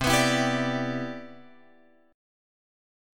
C Major 9th